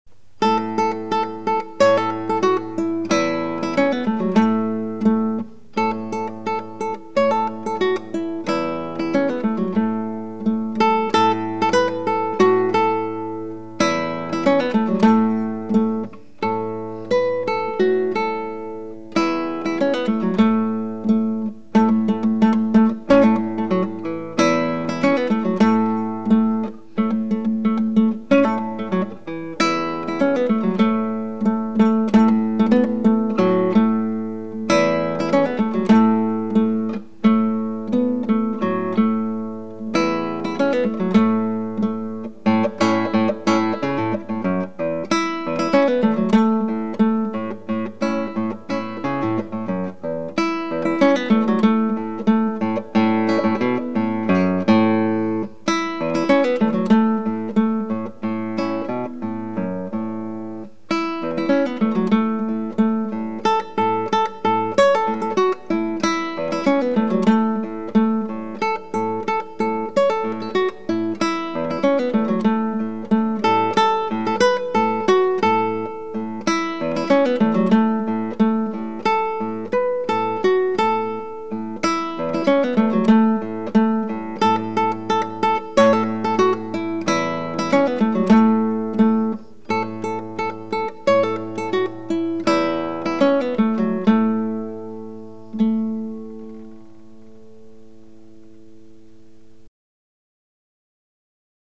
DIGITAL SHEET MUSIC - FINGERPICKING SOLO
Christmas Eve watch-night shout, Guitar Solo